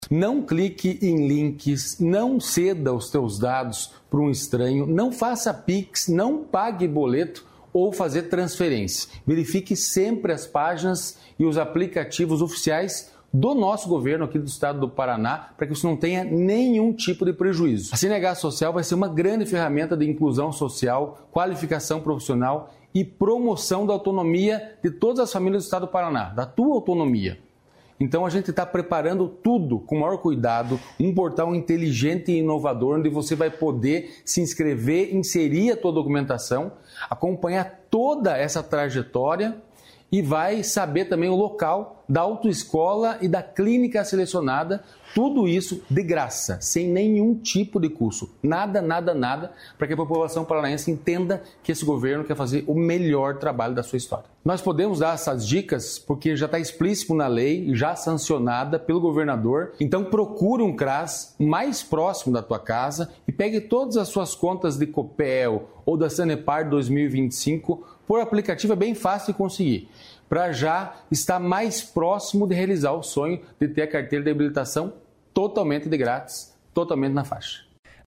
Sonora do diretor-presidente do Detran-PR, Santin Roveda, sobre golpe com uso do programa CNH Social